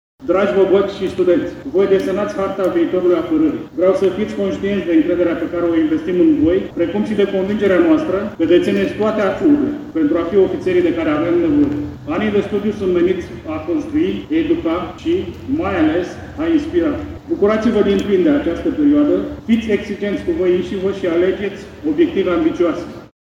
Peste 170 de boboci au început cursurile înaltei școli de aviație de la Brașov. Viitorii piloți au primit un îndemn mobilizator de la Șeful Statului Major al Armatei Române, general-maior Leonard-Gabriel Baraboi: